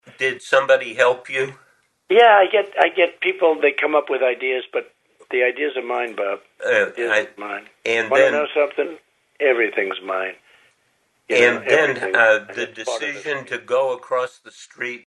“I get, I get people. They come up with ideas. But the ideas are mine, Bob. The ideas are mine,” Trump told Woodward in a June 2020 interview.